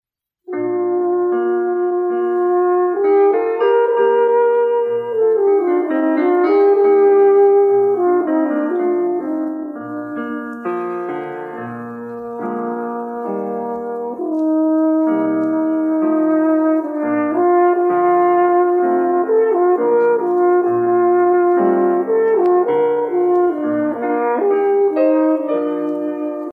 Sample – Digital Post-Processing and Noise Reduction
This example is instrumental music that was recorded off the radio 40 years ago to an audio cassette. It demonstrates the effects of our post-processing and digital noise reduction techniques.
AFTER – Click the play button below to hear the recording after we used digital post-processing to convert this cassette to a digital format.
audio-tape-2-after.mp3